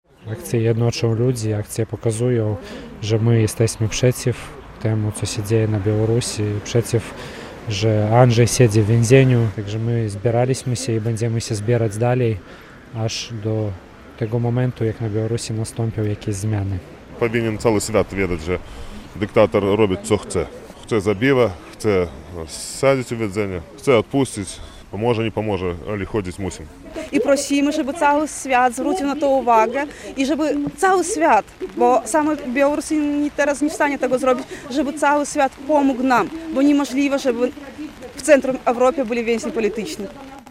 Relacja
Informacje przekazano podczas akcji solidarności z zatrzymanym dziennikarzem i liderem polskiej mniejszości na Białorusi, która odbyła się obok pomnika księdza Jerzego Popiełuszki w Białymstoku.